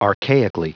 Prononciation du mot archaically en anglais (fichier audio)
Prononciation du mot : archaically